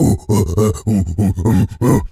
pgs/Assets/Audio/Animal_Impersonations/gorilla_chatter_05.wav at master
gorilla_chatter_05.wav